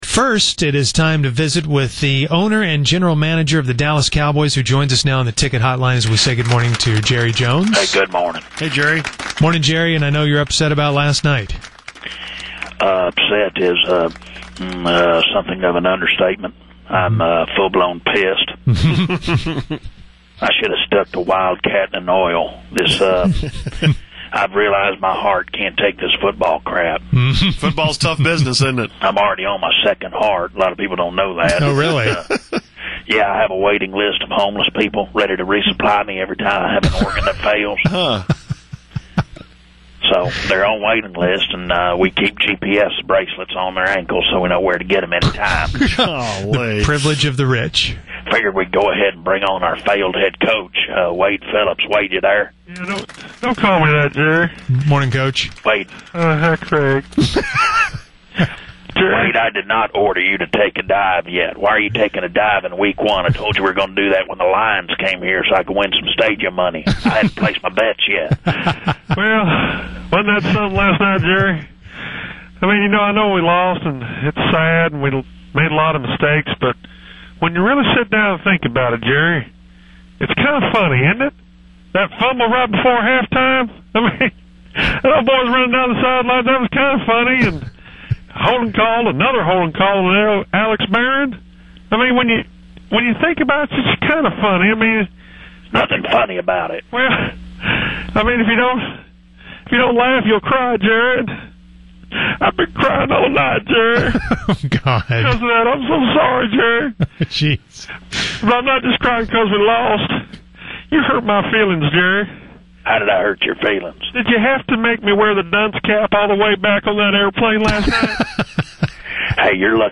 As usual, the Musers chat with Fake Jerry and Fake Wade after last night’s disappointing loss. Poor Wade is quite distraught.